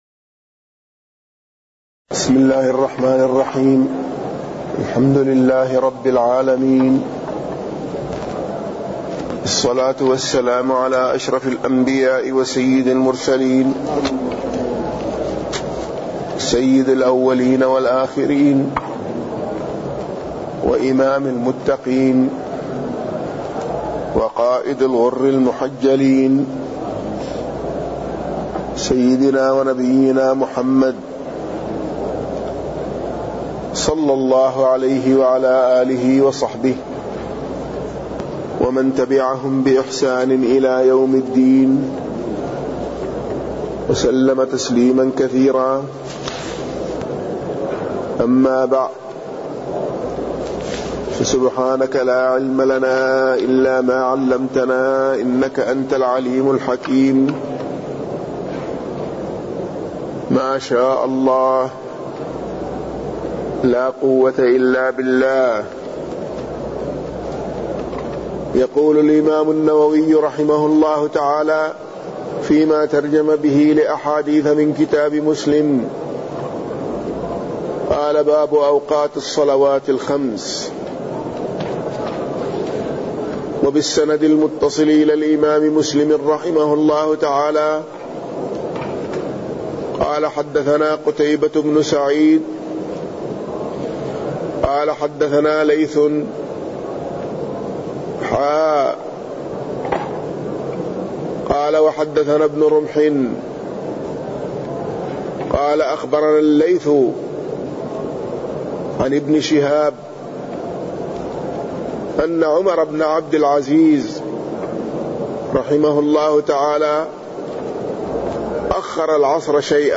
تاريخ النشر ٢١ شوال ١٤٢٩ هـ المكان: المسجد النبوي الشيخ